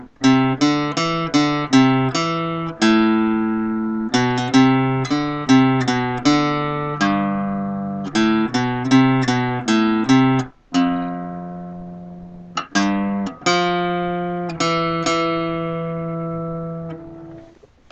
(Forgive my bad guitar interpretation!)